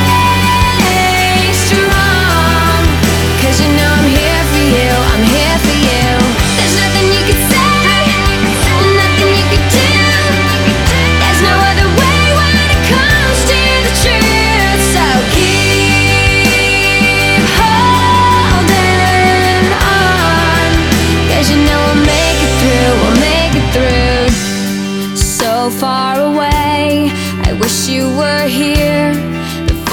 • Pop
power ballad